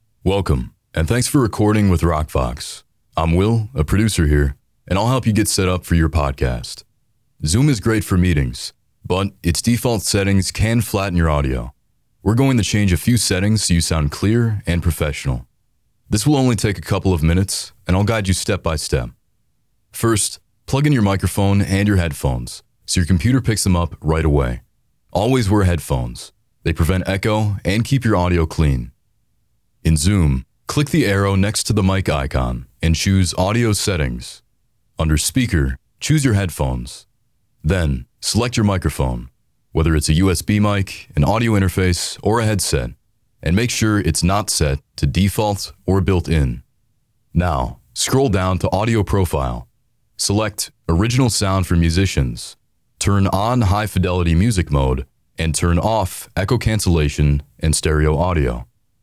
Voice Over Demos
Narration Sample
Broadcast Quality Studio